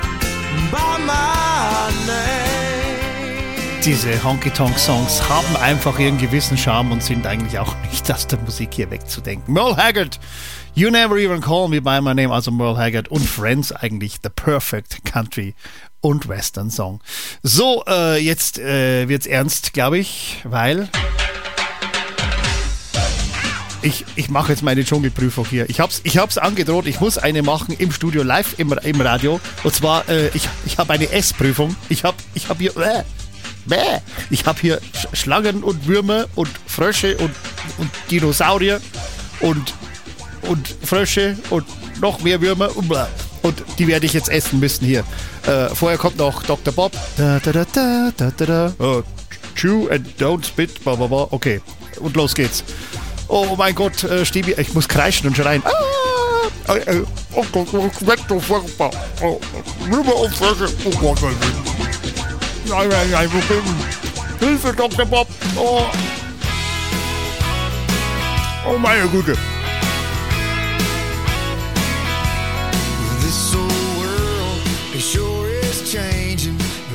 Dschungelprüfung im Radio
Was es nicht alles gibt, da habe ich doch glatt eine Dschungelprüfung während der Show gemacht!